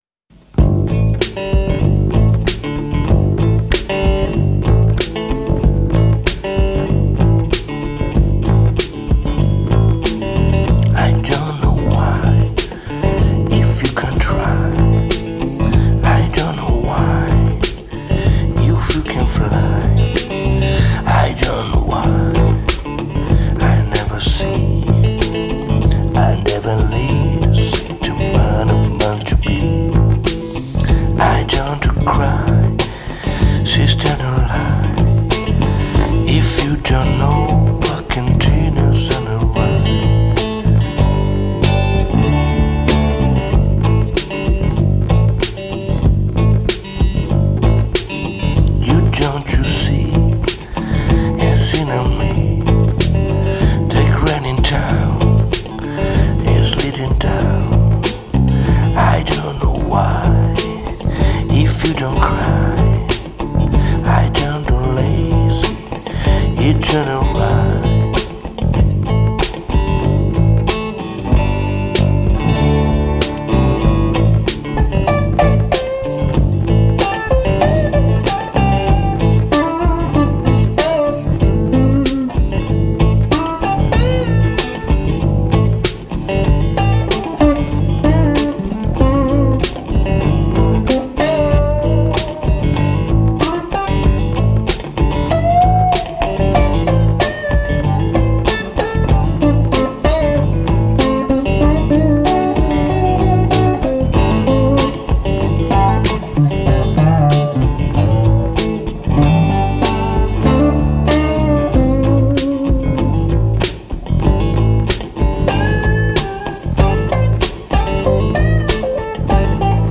il resto è batteria elettronica Roland